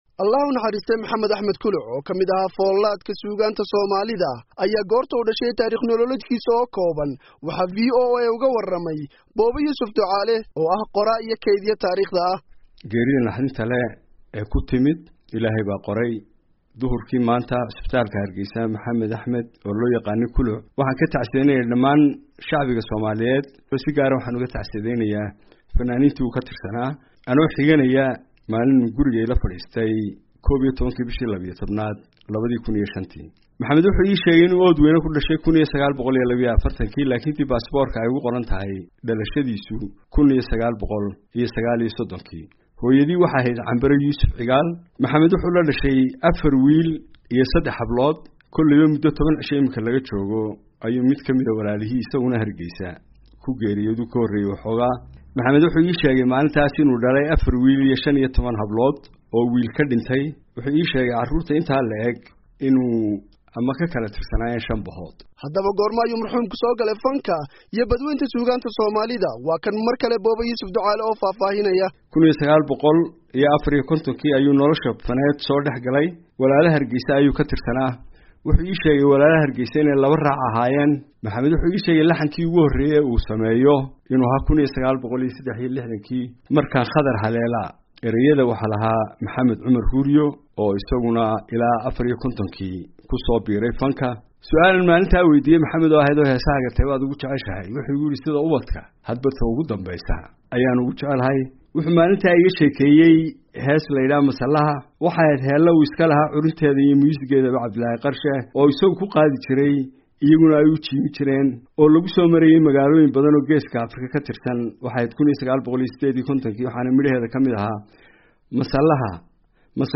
Taariikh nololeedka Fanaanka iyo weliba waayihiisii fanka warbixintan waxaa ku eegaya